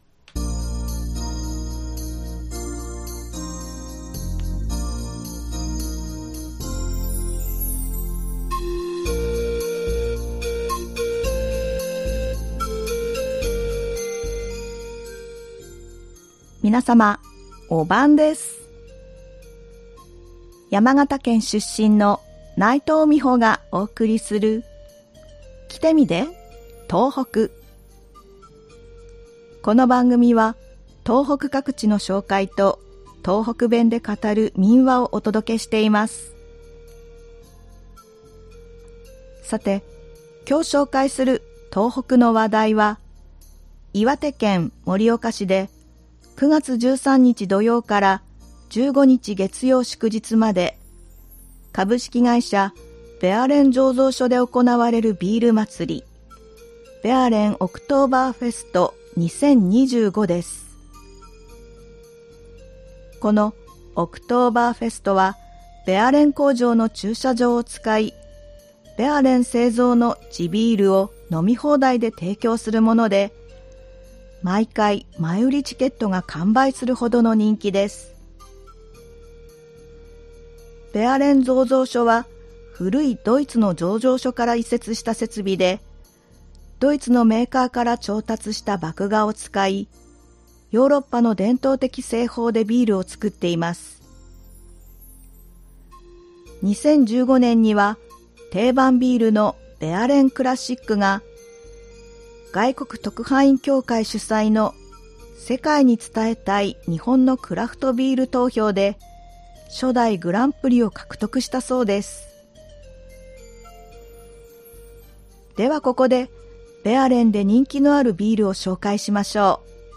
この番組は東北各地の紹介と、東北弁で語る民話をお届けしています。
ではここから、東北弁で語る民話をお送りします。今回は岩手県で語られていた民話「瓜こ姫こ」です。